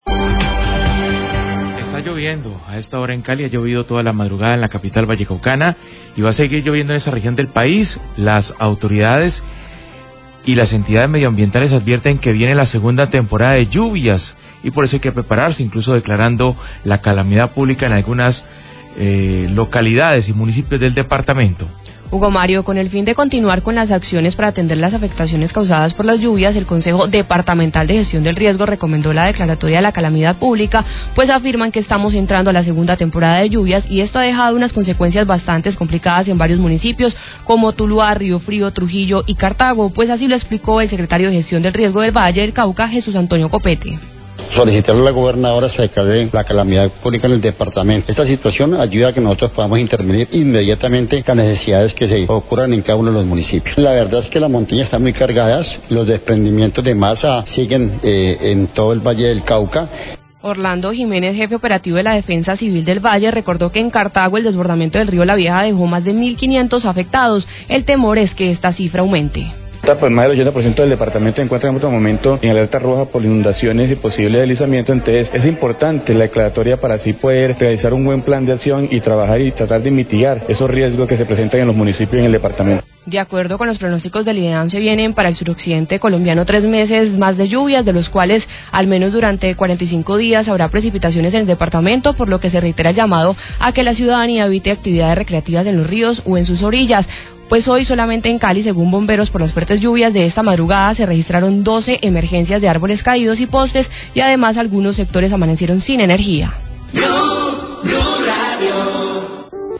Radio
El Consejo Departamental de Gestión del Riesgo recomendó la declaratoria de calamidad pública para poder atender las afectaciones causadas por las fuertes lluvias que se esperan  durante los siguientes 45 días. Declaraciones del Secretario de Gestión del Riesgo del Valle, Jesús Antonio Copete.